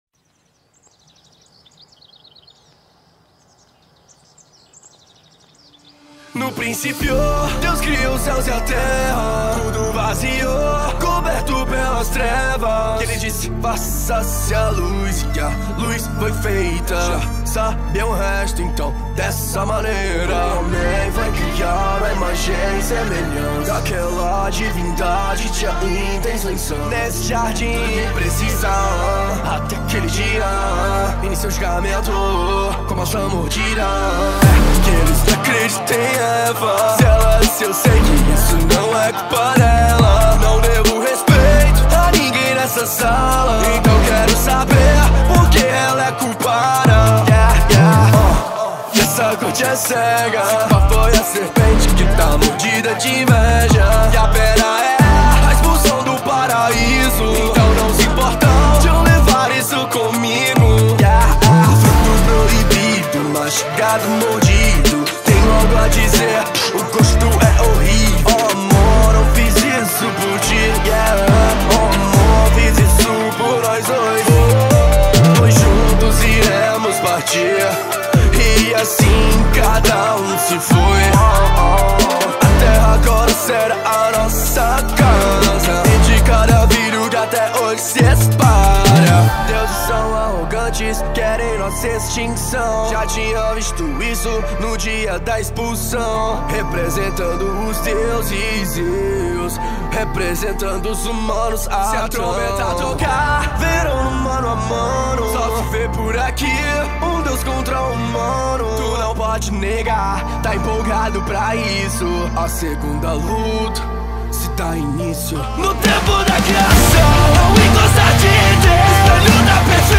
2025-02-23 15:52:38 Gênero: Rap Views